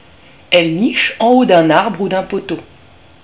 Le cri de la corneille. Son cri est rauque comme une voix cassée.